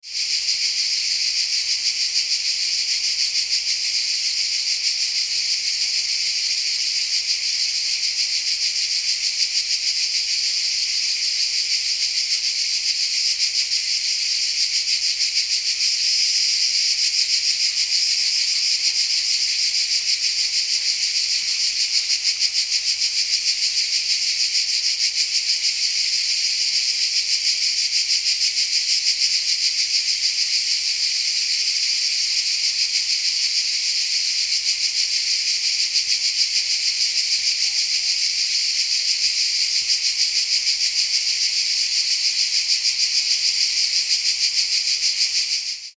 Summer Cicadas Chirping – Mediterranean Nature Ambience Sound Effect
Experience premium summer cicadas chirping in an authentic Mediterranean nature ambience, organically recorded on location in Greece during a hot summer day near the beach. This high-quality nature sound effect captures clear insect sounds and a realistic daytime atmosphere, making it ideal for film, TV, advertising, apps, meditation, and commercial media projects.
Summer-cicadas-chirping-mediterranean-nature-ambience-sound-effect.mp3